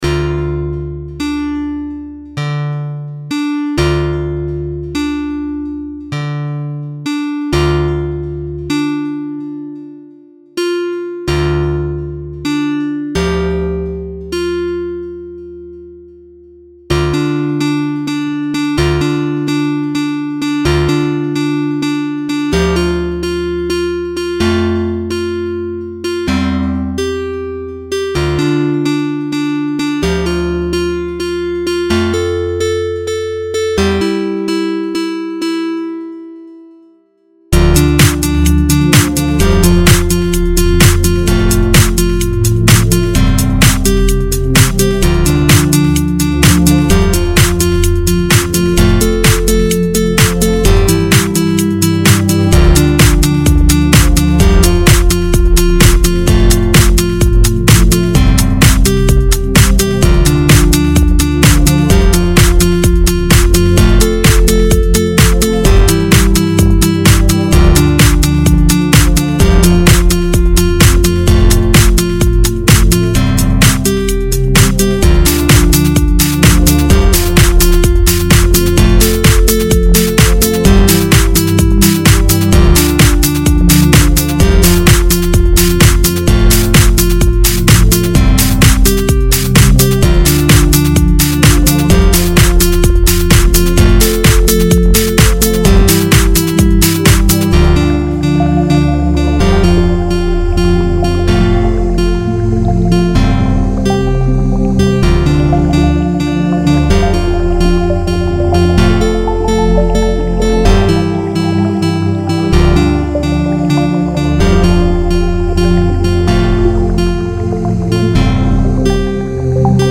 Electro mix , I hope you enjoy it, 128 kbps
Genre Electronica